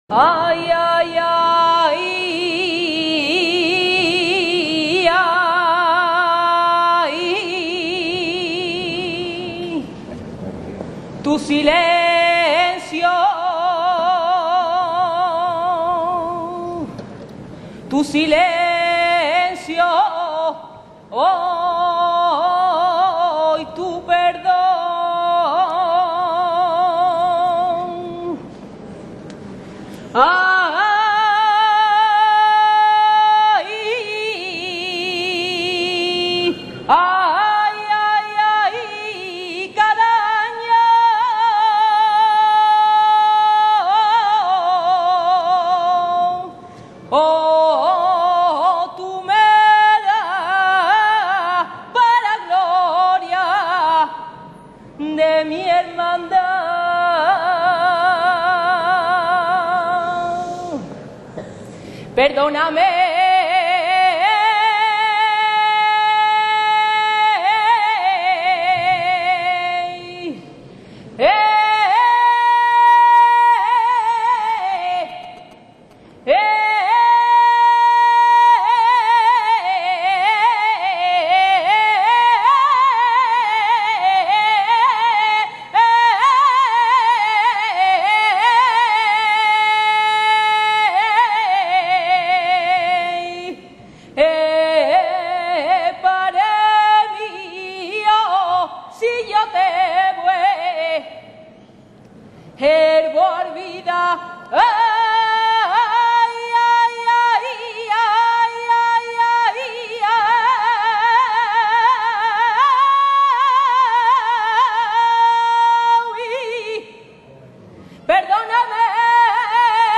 Una de las Saeteras que acompañaron al traslado de Ntro Padre Jesús Redentor Cautivo entonando una sentida y profunda emoción hecha saeta.
saetera-002.wma